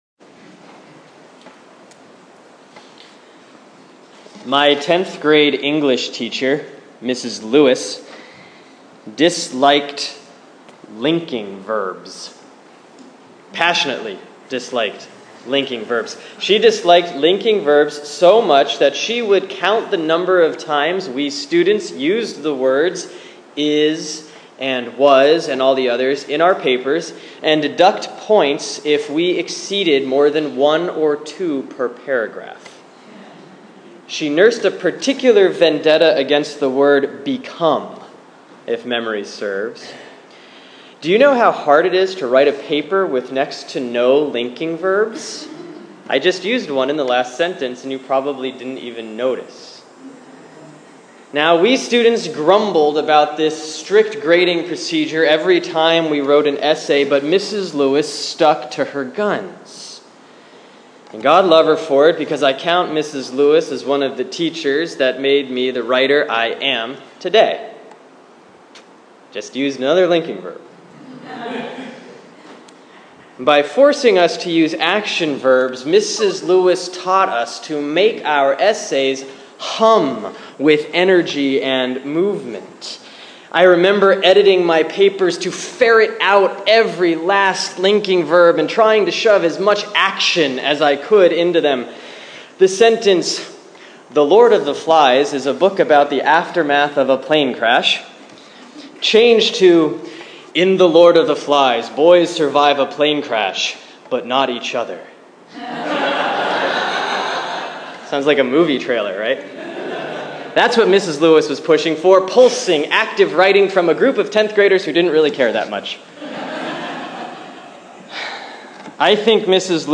Sermon for Sunday, August 30, 2015 || Proper 17 || James 1:17-27